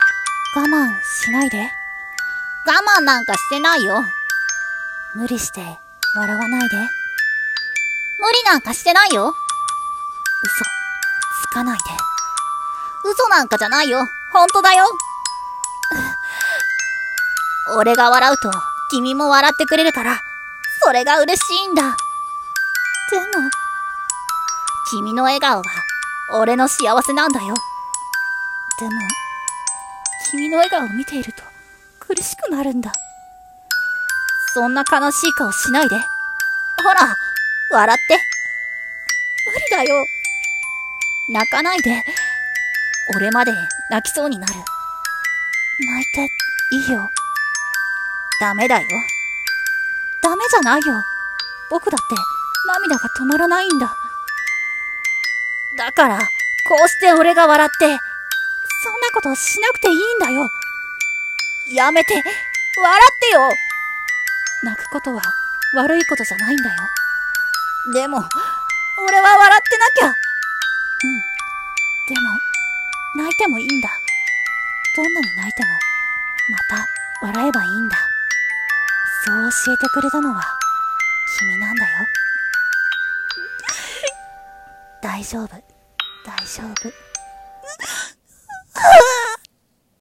声劇「嘘吐きピエロ」